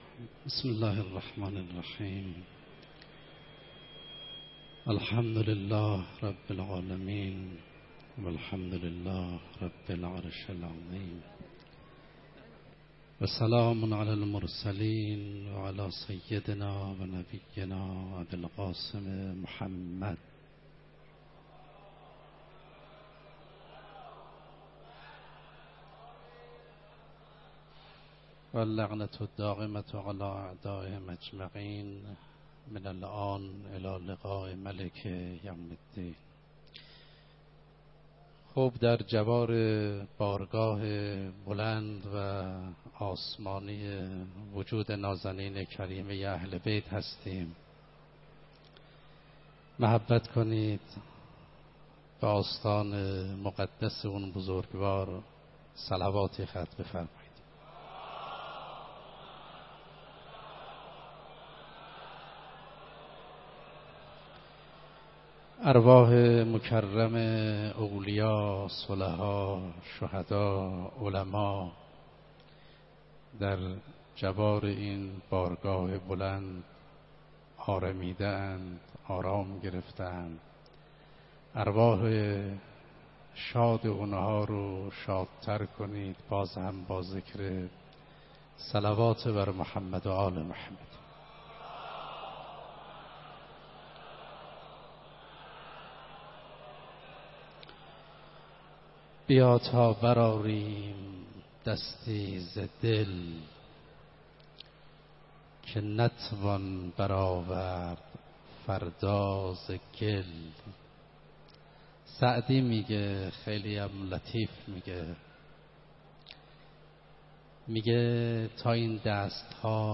31 فروردین 96 - حرم حضرت معصومه - سخنرانی